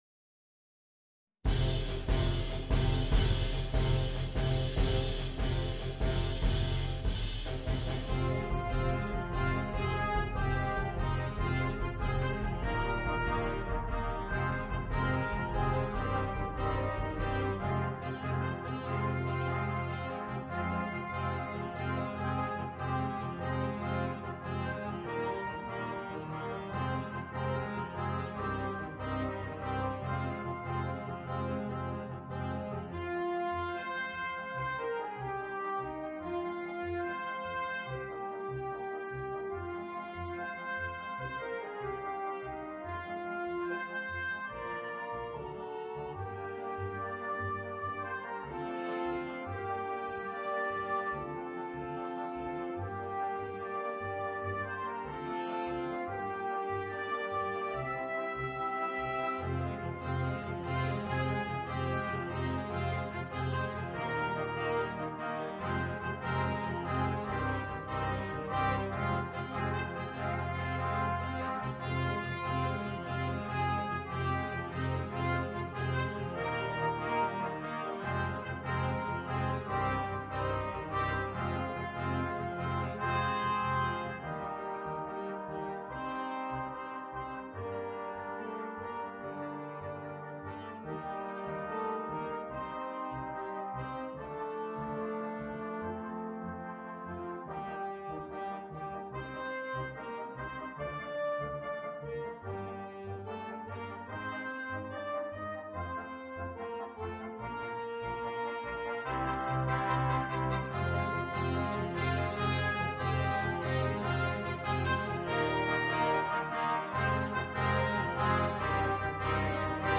на смешанный состав